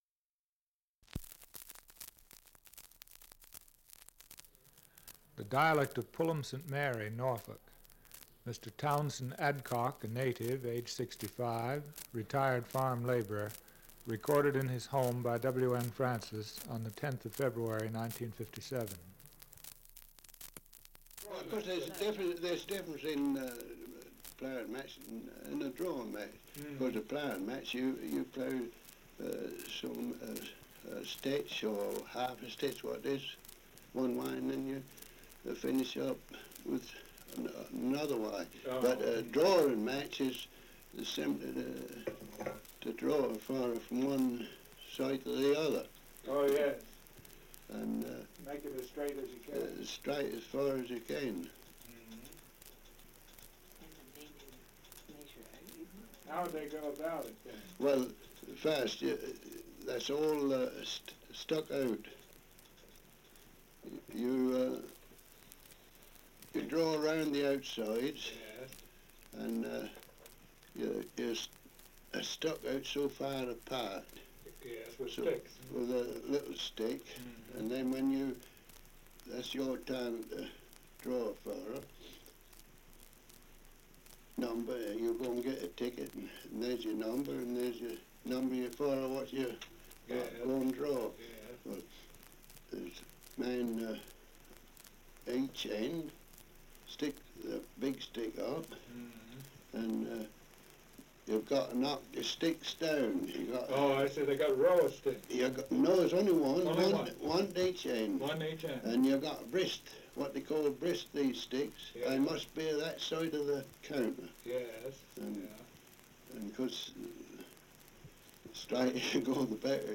Survey of English Dialects recording in Pulham St Mary, Norfolk
78 r.p.m., cellulose nitrate on aluminium